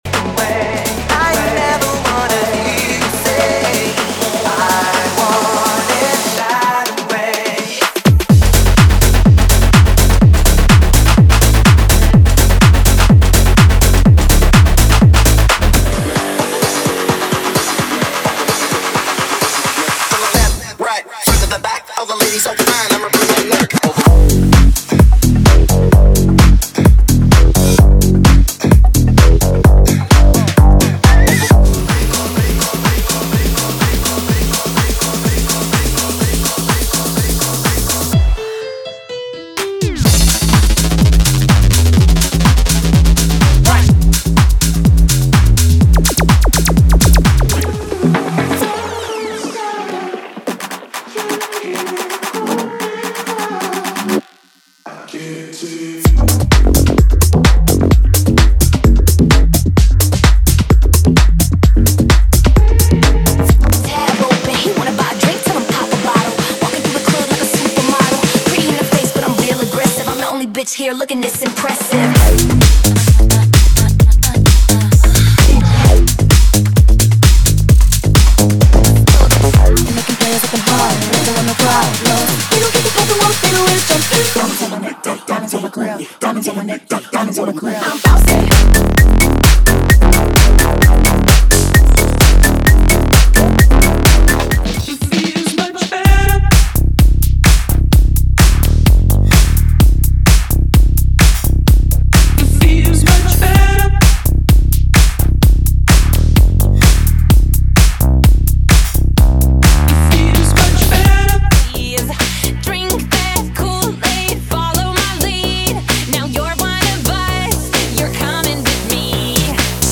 Os Melhores Tech House do momento estão aqui!!!
• Versões Extended
• Sem Vinhetas